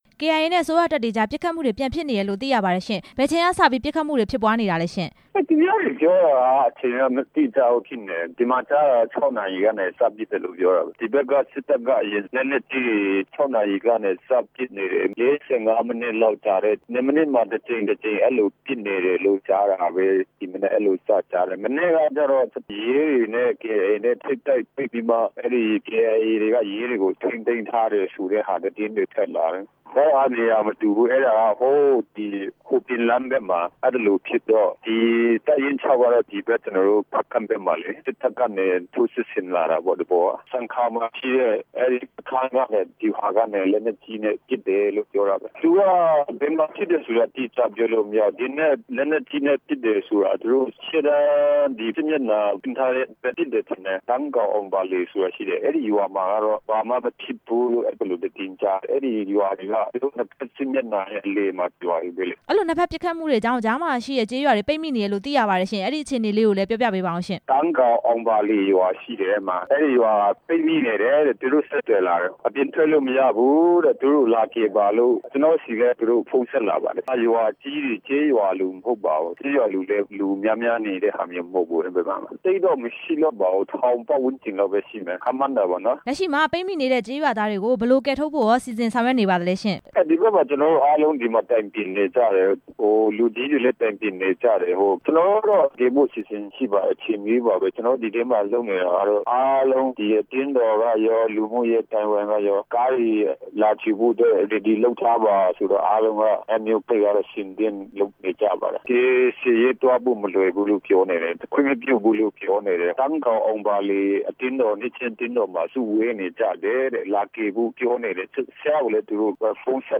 ကချင်ပြည်နယ်တိုက်ပွဲမှာ ဒေသ ခံ ၂၀၀၀ လောက် ပိတ်မိနေတဲ့ အကြောင်း မေးမြန်းချက်